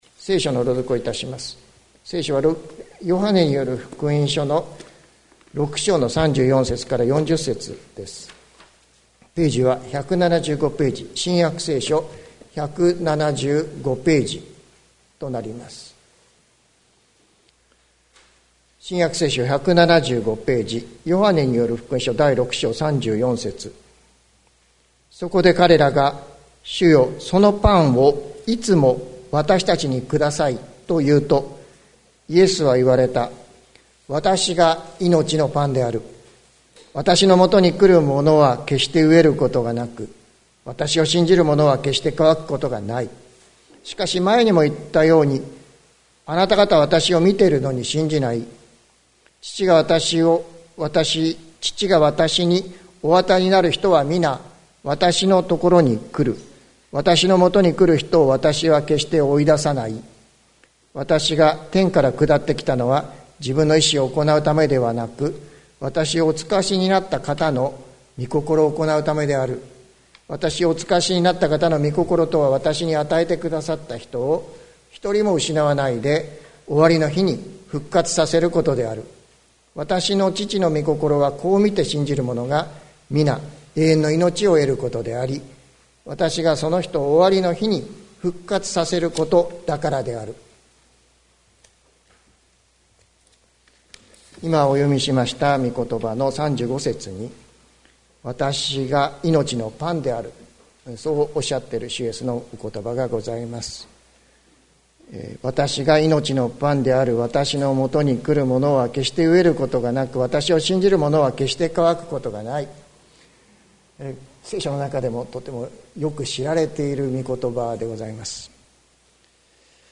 2022年05月08日朝の礼拝「命のパンの恵みに生かされ その２」関キリスト教会
説教アーカイブ。